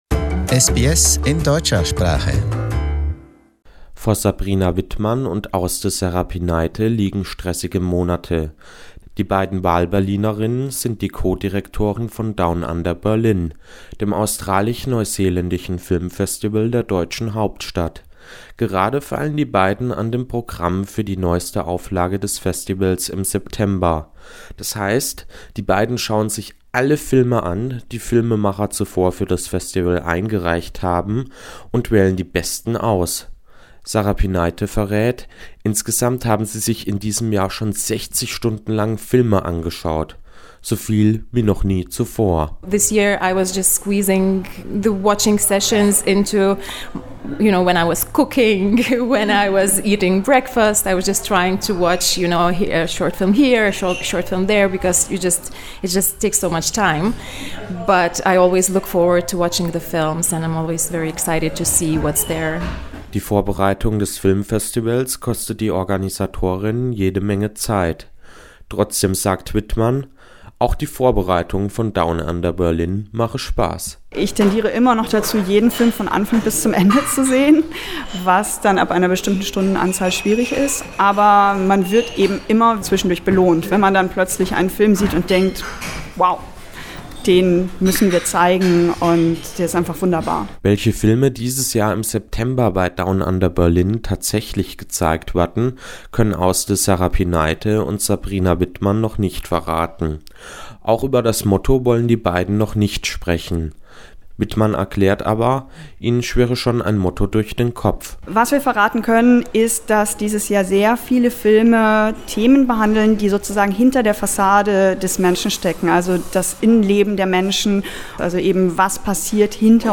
Germany, in turn, has Down Under Berlin, Europe's leading festival for Australian & New Zealand film, in September. For more, listen to the interview.